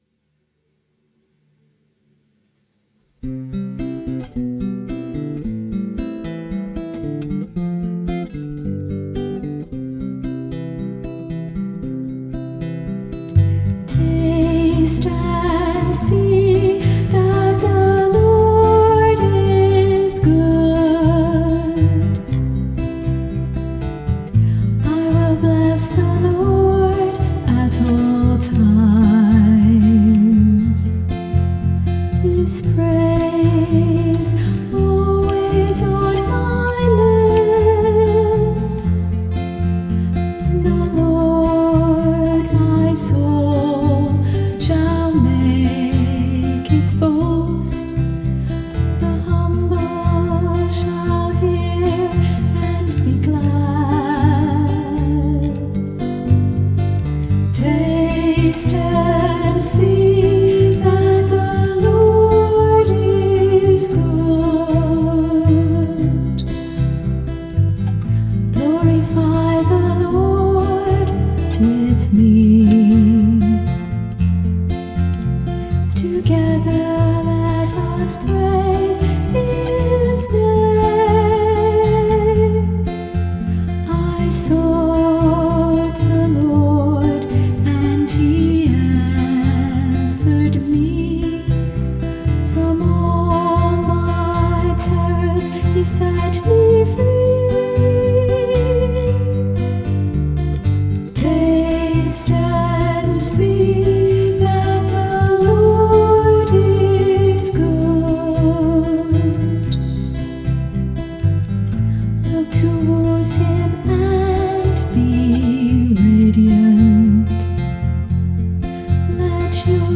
The music for the psalm is an original OLOR composition.
Music by the Choir of Our Lady of the Rosary RC Church, Verdun, St. John, Barbados.